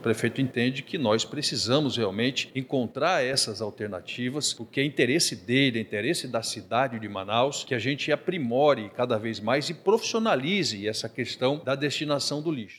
O vice-prefeito de Manaus, Marcos Rotta, que se reuniu com os representantes da empresa, disse que é de interesse da cidade dar uma destinação sustentável ao lixo produzido na capital amazonense.